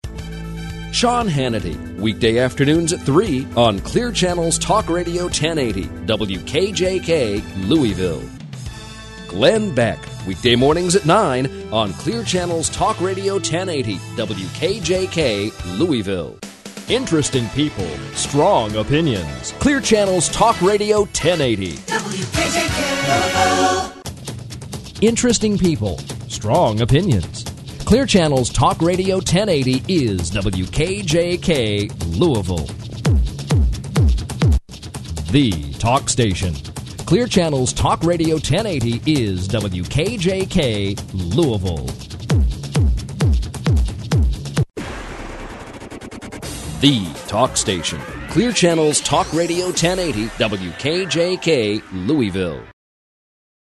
WKJKLegalIDs.mp3